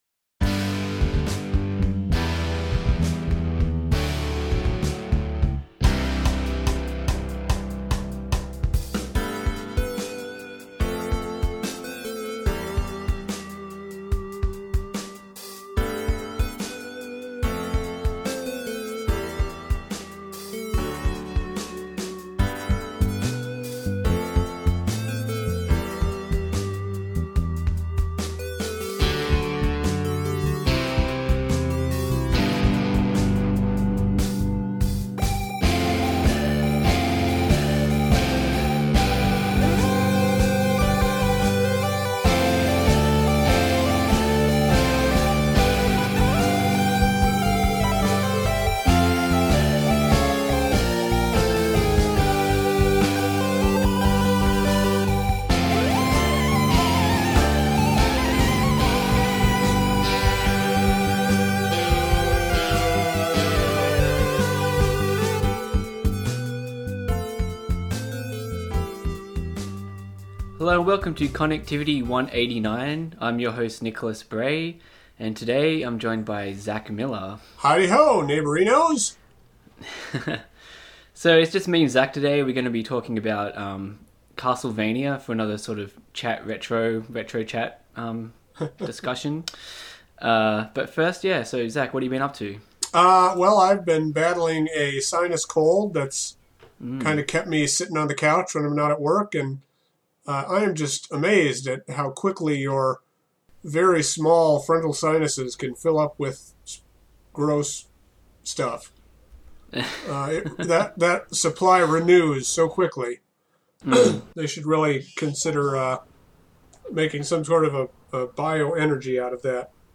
This week on Connectivity it's a two man show.